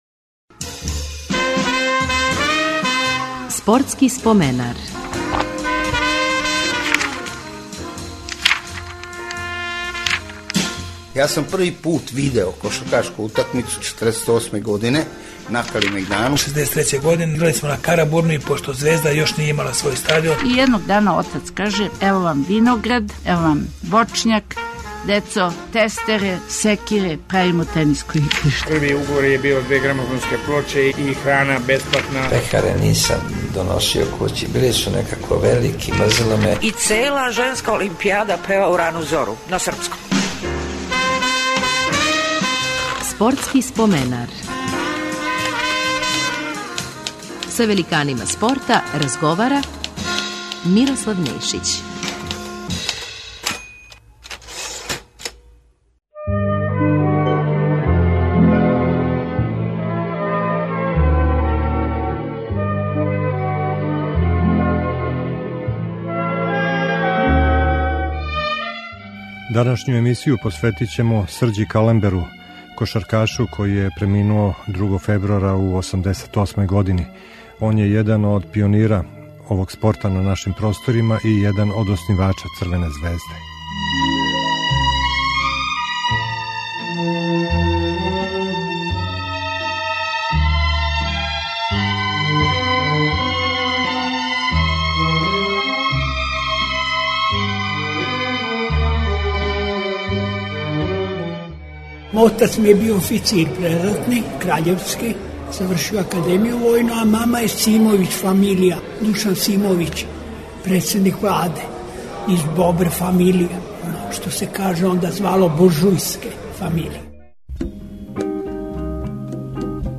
У емисији ће бити емитована Калемберова сећања на спортске активности у Београду током Другог светског рата, основању Спортског друштва Црвена звезда, првим послератним утакмицама државног тима, путовањима у иностранство...